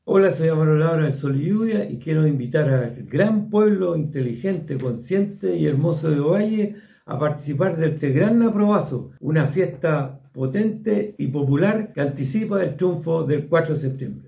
Por su parte, Amaro Labra, cantautor de Sol y Lluvia hizo un llamado a las y los vecinos de Ovalle a participar en este evento.